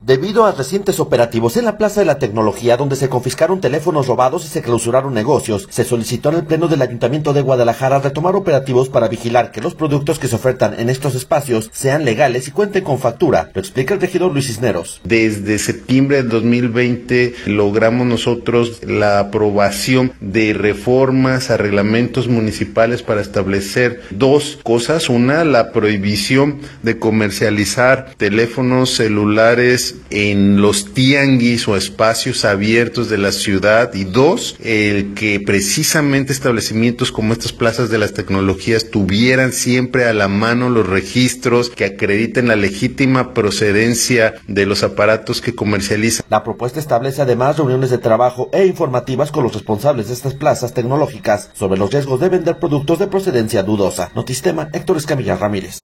Debido a recientes operativos en la Plaza de la Tecnología donde se confiscaron teléfonos robados y se clausuraron negocios, se solicitó en el pleno del ayuntamiento de Guadalajara retomar operativos para vigilar que los productos que se ofertan en estos espacios sean legales y cuenten con factura. Lo explica el regidor Luis Cisneros.